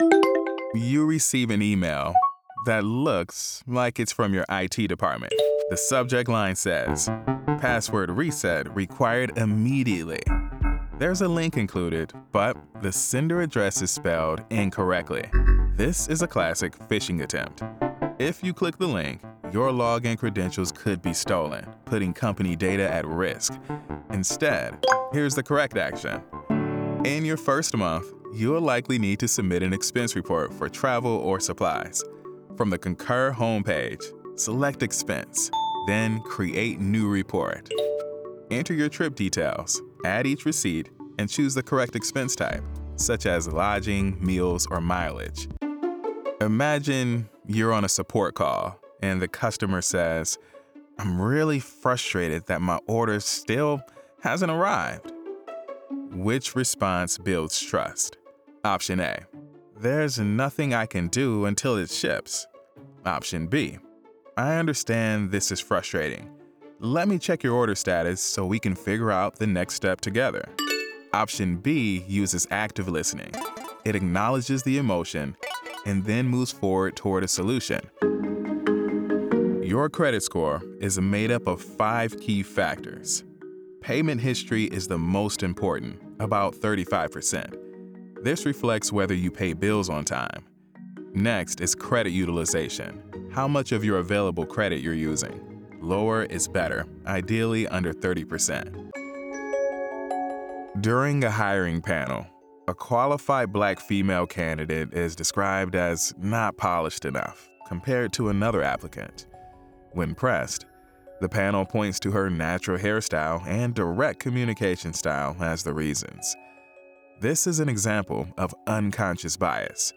E-Learning - Friendly, Conversational, Professional
From his home studio he narrates voiceovers with an articulate, genuine, conversational, and authentic feel.